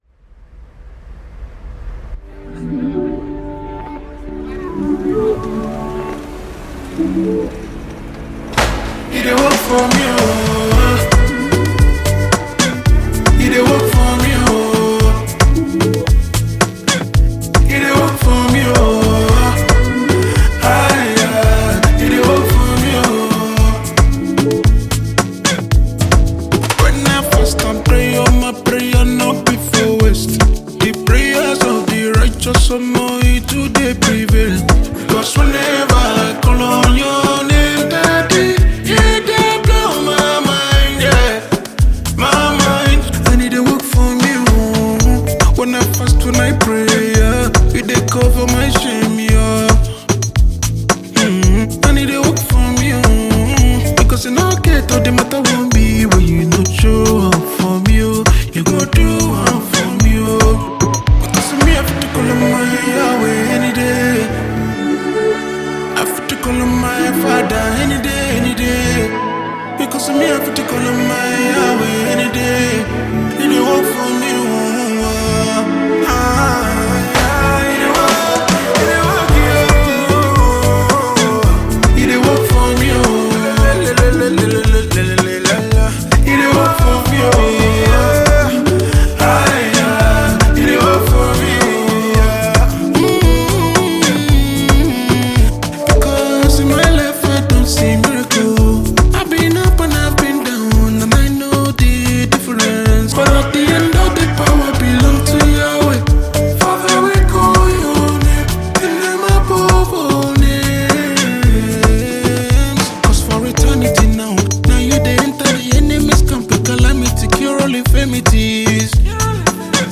March 12, 2025 Publisher 01 Gospel 0